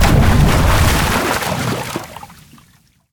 • 声道 單聲道 (1ch)